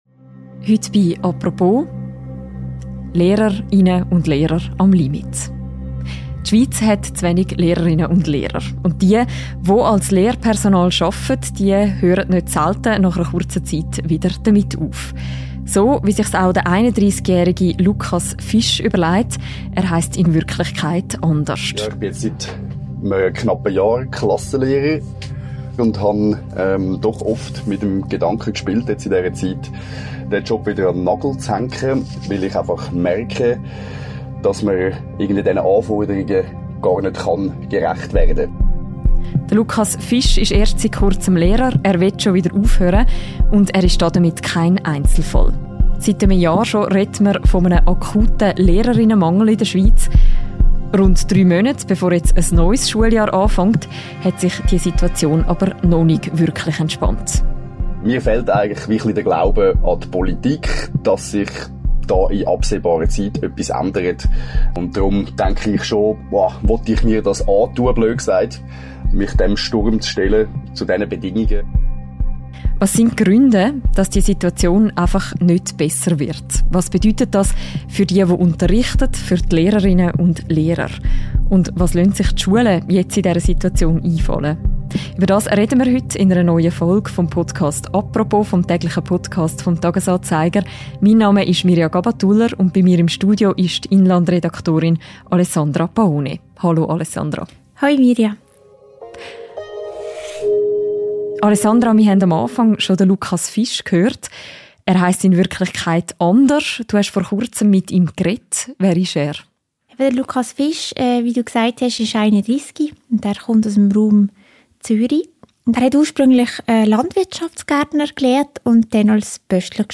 Zwei Lehrpersonen erzählen, wie steigende Anforderungen an den Beruf der Lehrerin oder des Lehrers sowie ein grosses Pensum nebst dem eigentlichen Unterricht sie zermürben und sie sich von der Politik alleine gelassen fühlen.